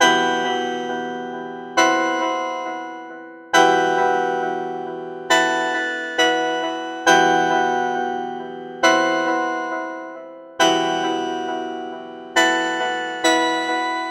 复古合成器的恐怖和弦1
描述：E小调的复古合成器和弦。延迟的
Tag: 136 bpm Hip Hop Loops Synth Loops 2.38 MB wav Key : E